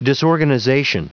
Prononciation du mot disorganization en anglais (fichier audio)
Prononciation du mot : disorganization